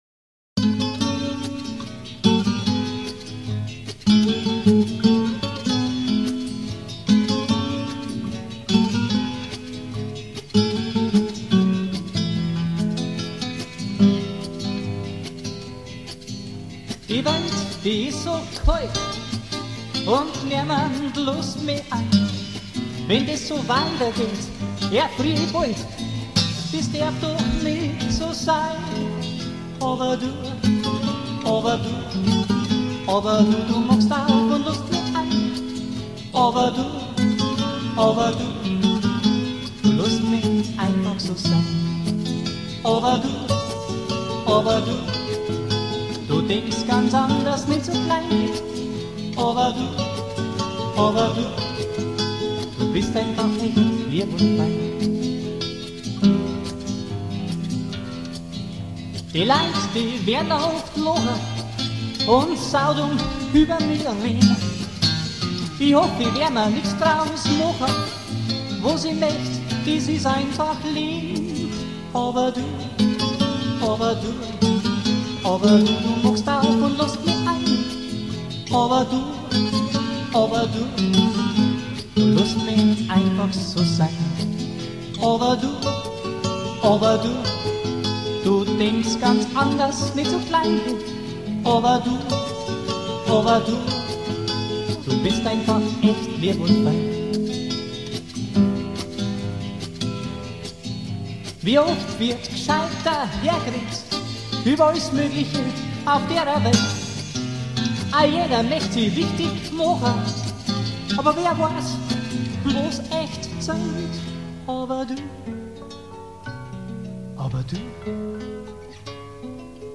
Produktion: CATSOUND Tonstudio Eggenfelden